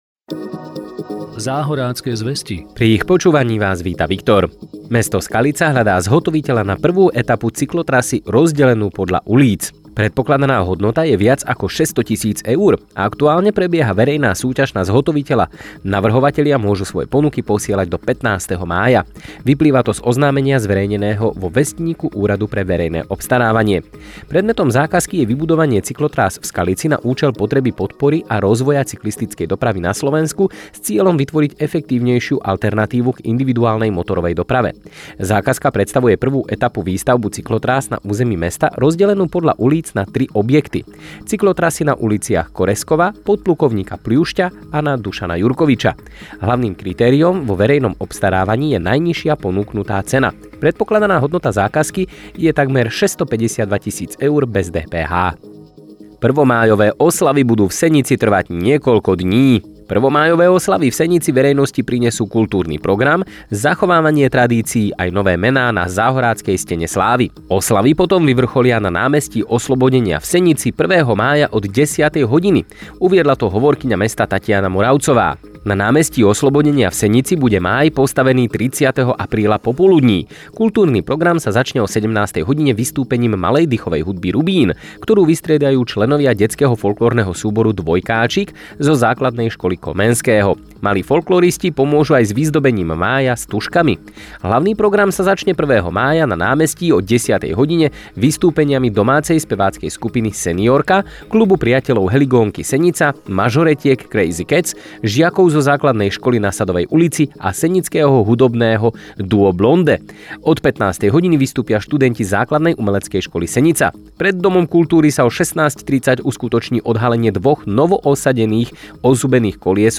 Spravodajský blok Záhorácke zvesti prináša aktuálne informácie zo Záhoria.